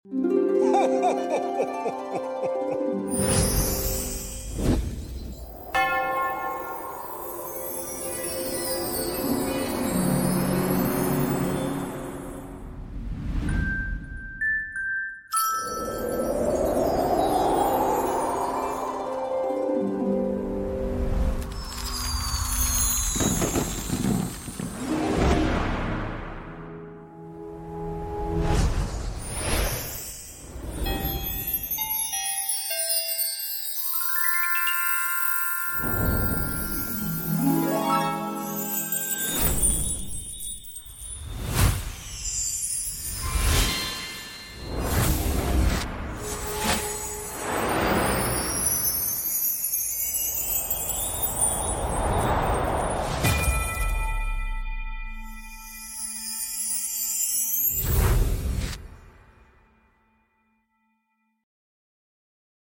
Christmas Transitions Sound Fx Pack!